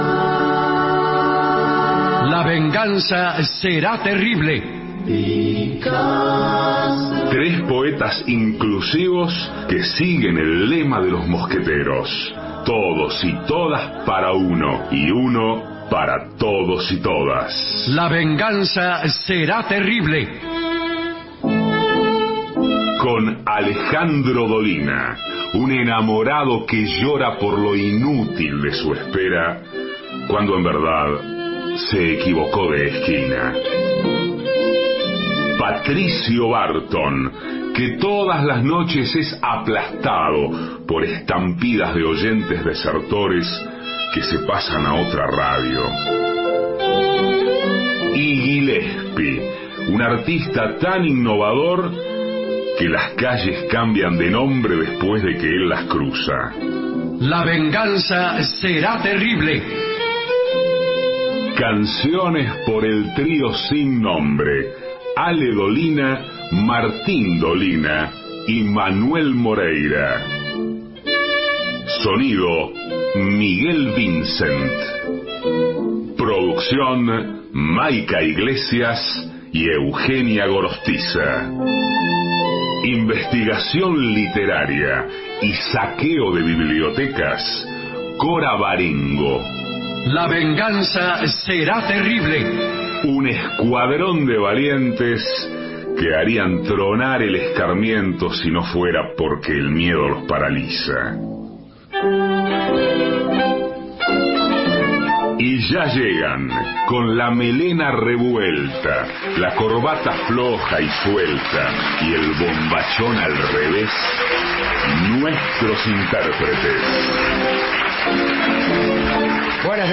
Segmento Humorístico • ¿Cómo seducir a una mujer en su auto?